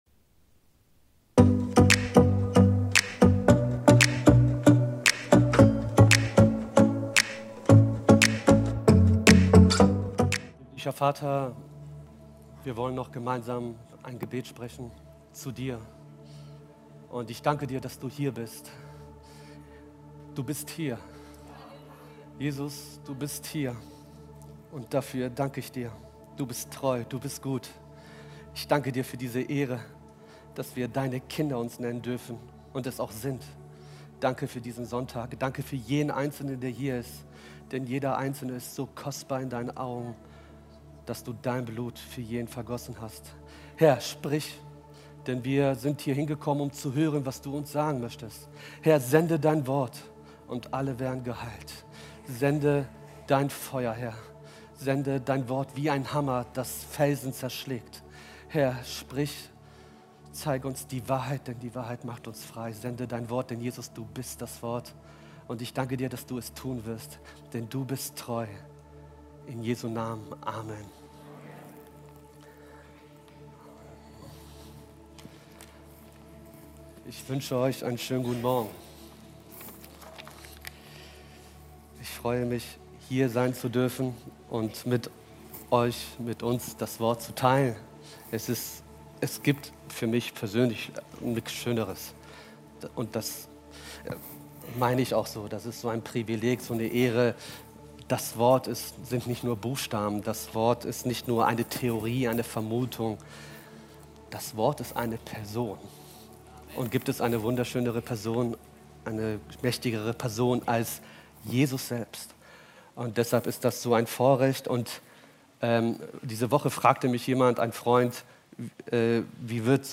Live-Gottesdienst aus der Life Kirche Langenfeld.
Kategorie: Sonntaggottesdienst Predigtserie: Hoffnung in rauen Zeiten